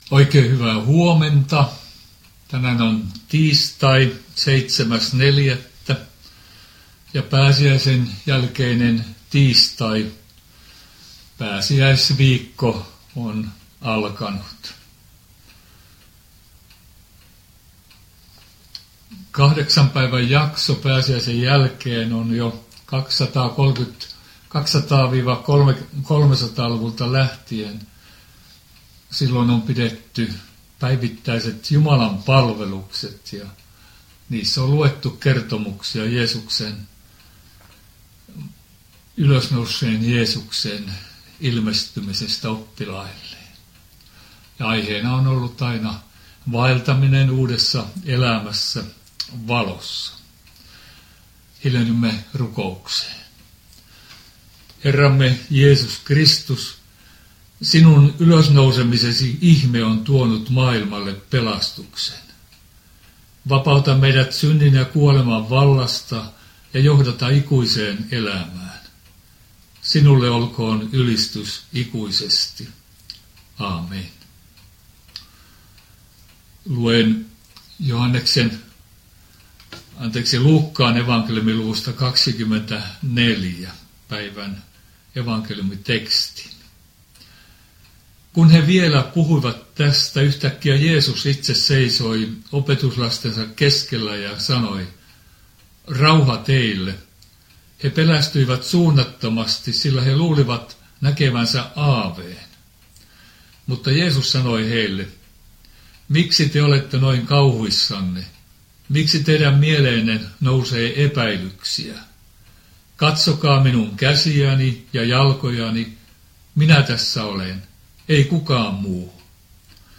nettihartaus Lohtajalla pääsiäsen jälkeisen viikon tiistaina Pohjana Luuk. 24:36-47 sekä Siionin kannel 335